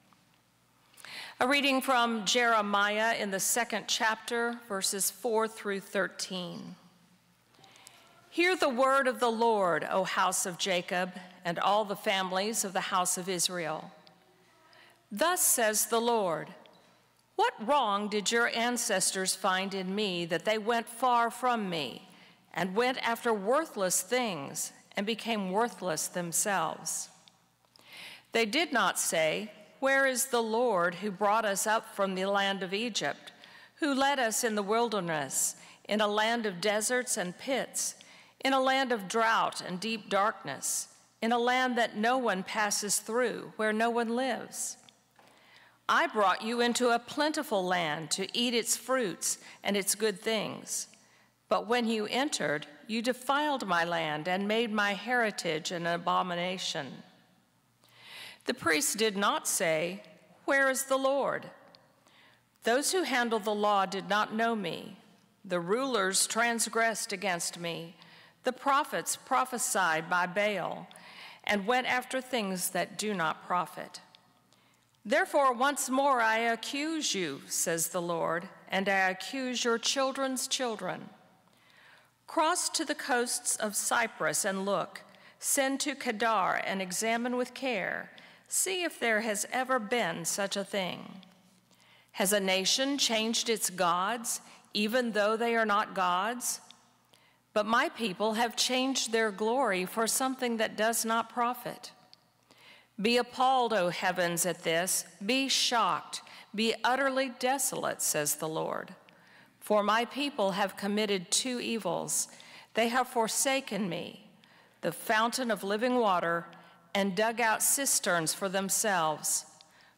Discover how to move from duty to devotion in your faith walk. A sermon on Jeremiah 2 to help you follow God from a place a love and rather than obligation.